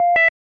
1 channel
L_MBEEP.mp3